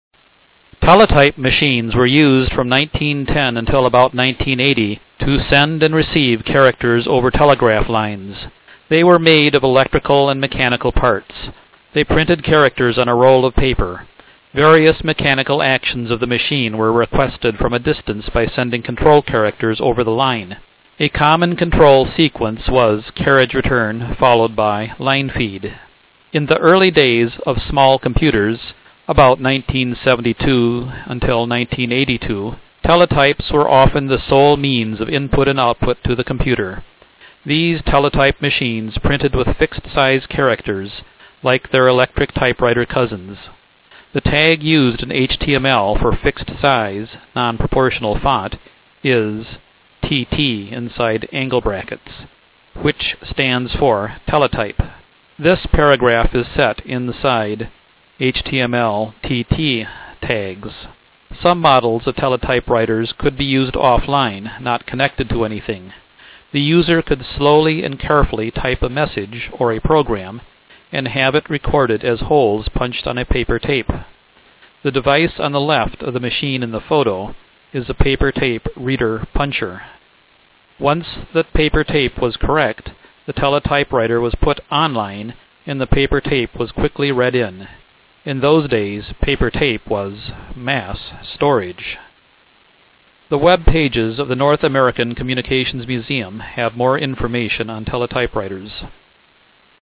The better ones smelled of fresh machine oil and chattered pleasantly as they worked.
Teletype Machines